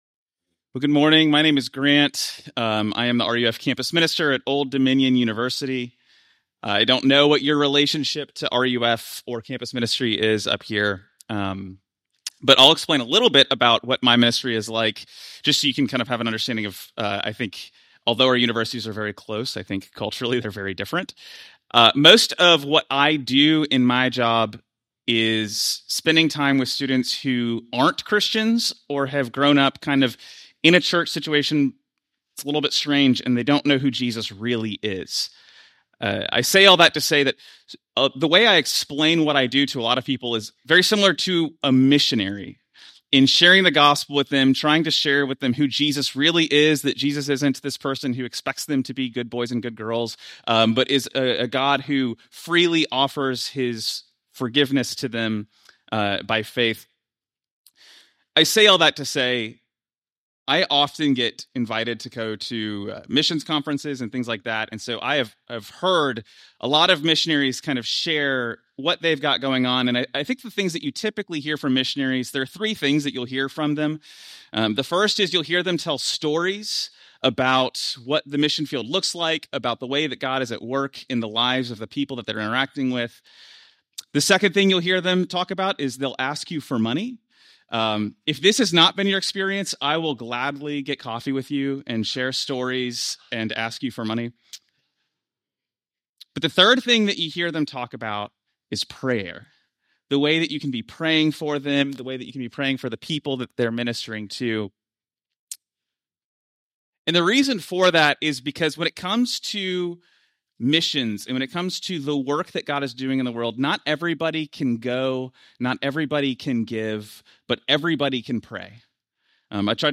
Sermons from our weekly Sunday worship service.